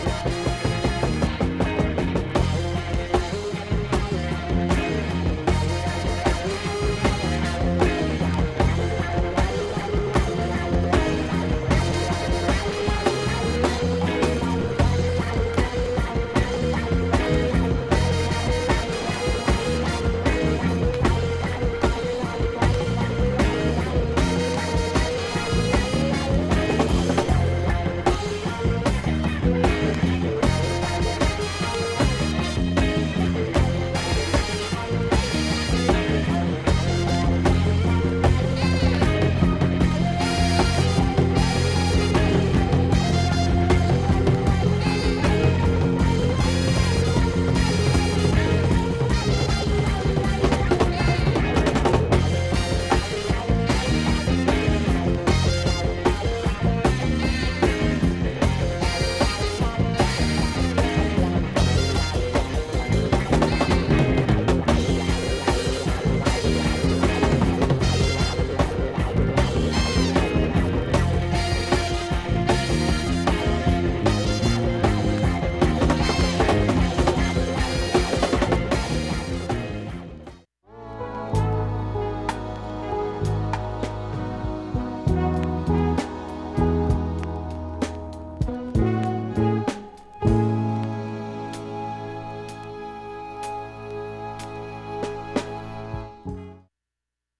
盤面きれいです音質良好全曲試聴済み。
(1m21s〜)C-2中盤にかすかなプツが６回出ます